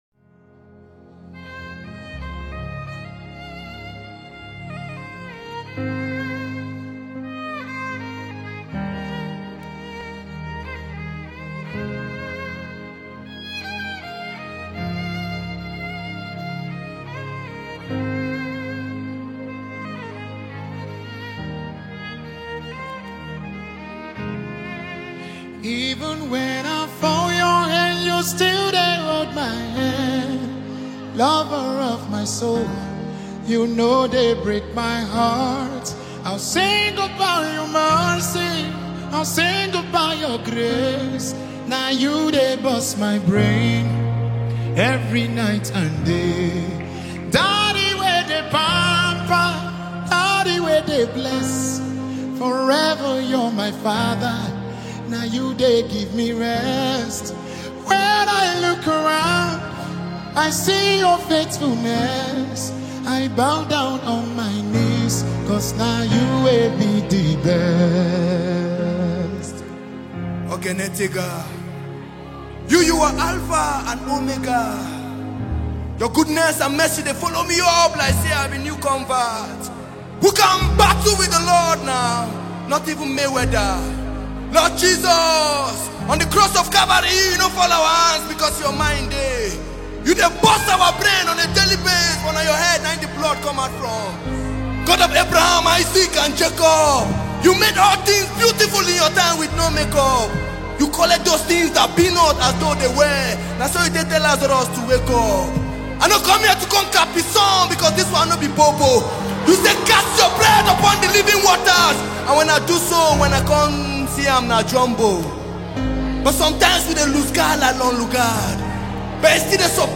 Well known and super talented Nigerian gospel singer